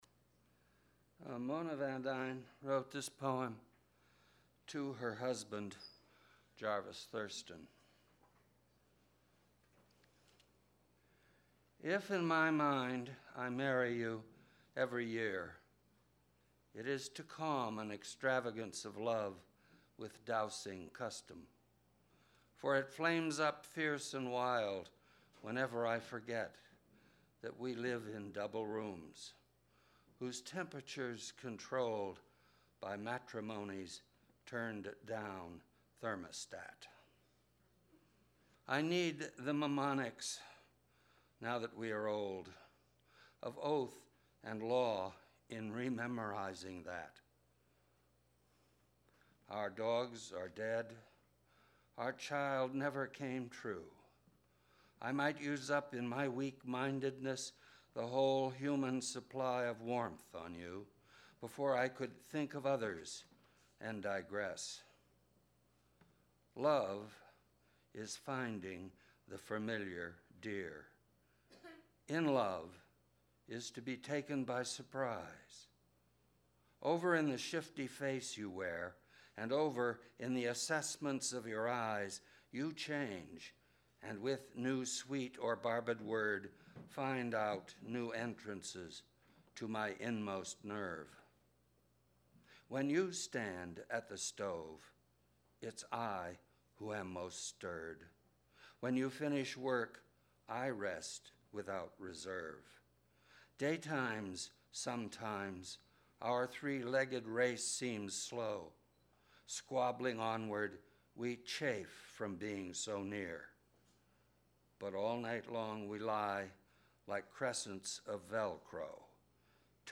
At an April 25, 2010 fundraiser at Blueberry Hill Restaurant, academics from the St. Louis area read works by deceased writers from or associated with St. Louis. This clip is of Gass reading “Late Loving” by Mona Van Duyn.
Audio Cassette Tape